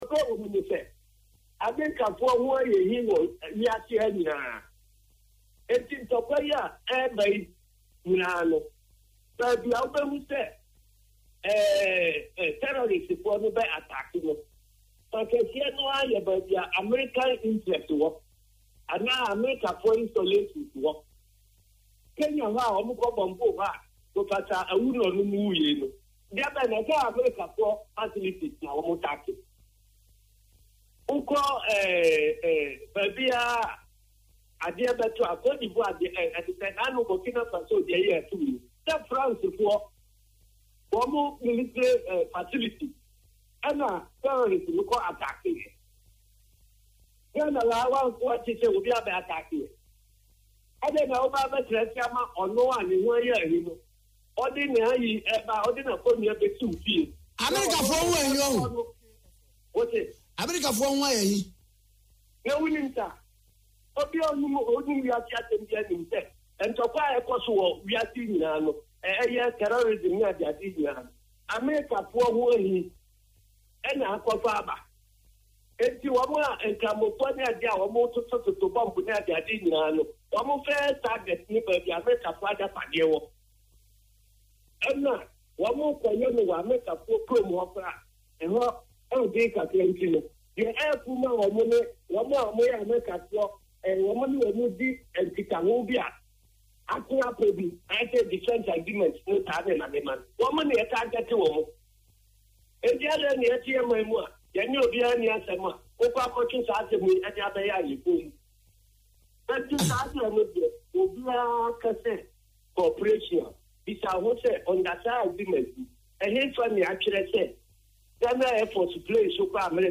“Our MPs should desist from it because it won’t work. They should rise up in arms against the move,” he said on Accra based Okay FM.